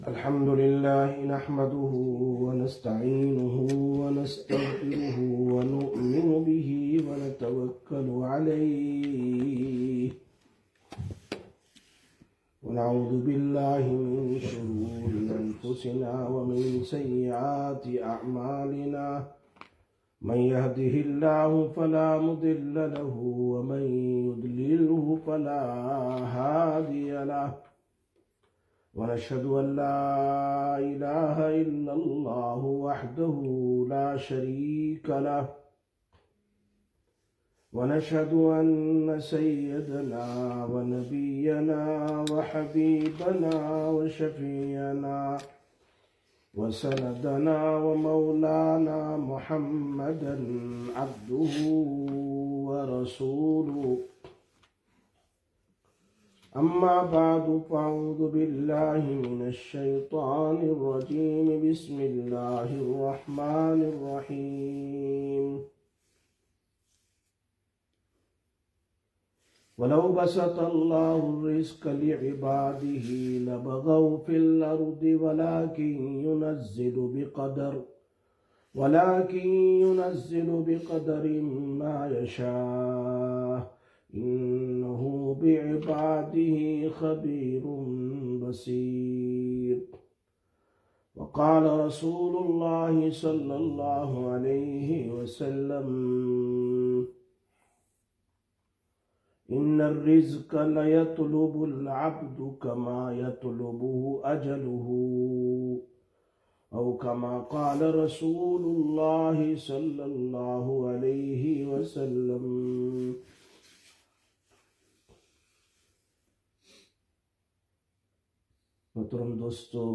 19/11/2025 Sisters Bayan, Masjid Quba